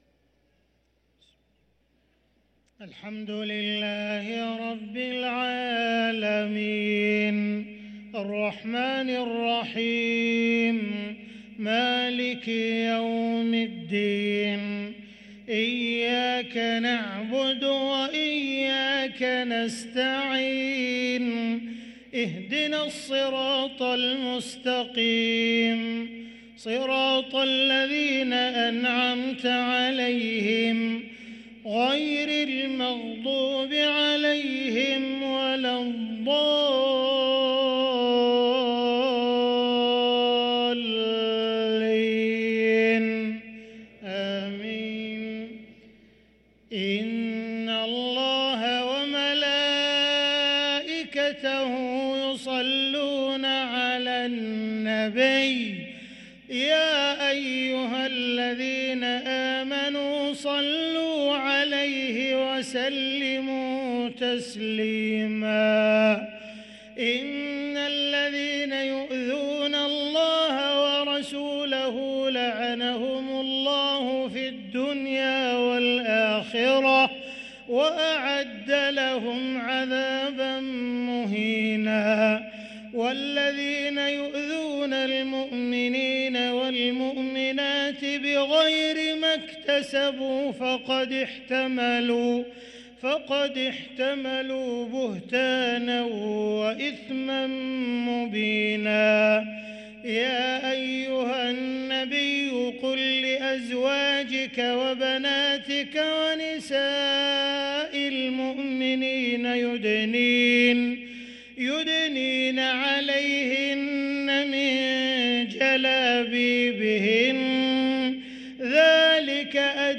صلاة العشاء للقارئ عبدالرحمن السديس 26 رجب 1444 هـ
تِلَاوَات الْحَرَمَيْن .